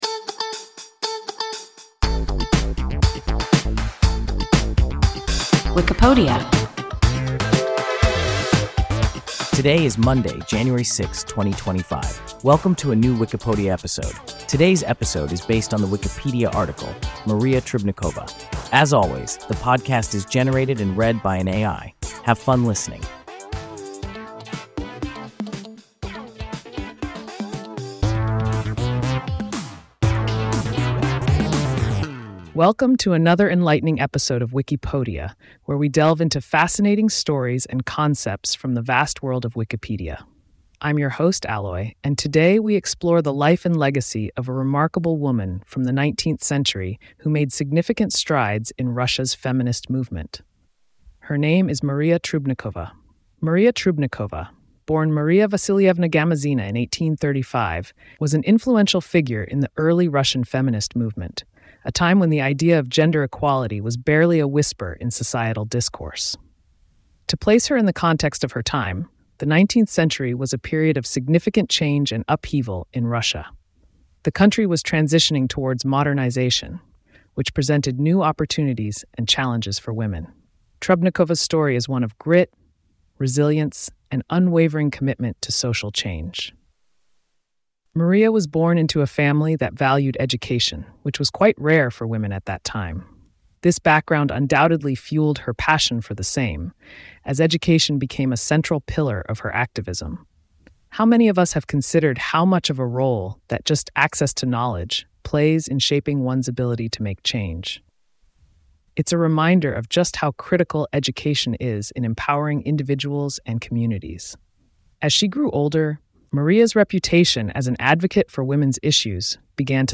Maria Trubnikova – WIKIPODIA – ein KI Podcast
Wikipodia – an AI podcast